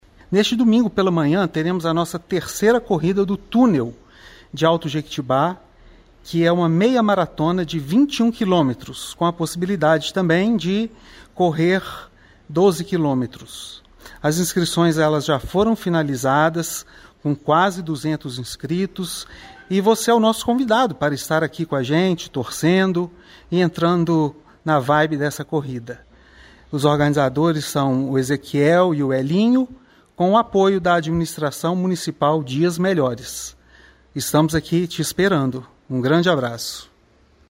Confira o convite do Secretário municipal de Cultura, Esporte, Lazer e Turismo, Fernando Sathler Breder